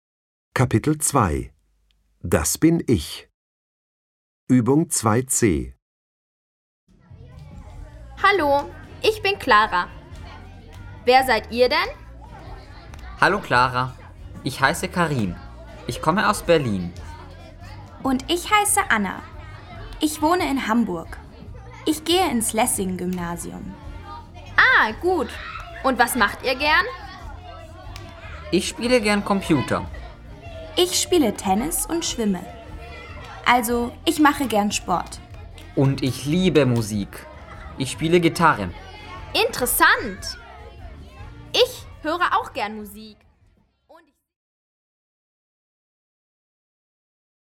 Hallás utáni gyakorlat.